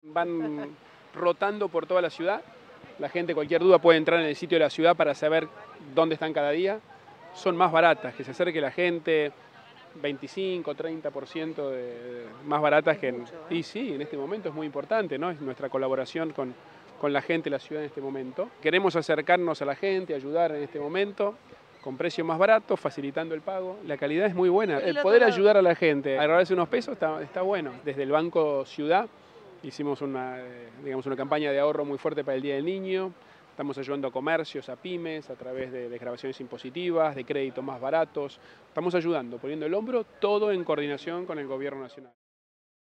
“Queremos acercarnos a la gente, ayudar en este momento, con precios más baratos y facilitando el pago”, sostuvo el Jefe de Gobierno durante una recorrida por una de las FIABs.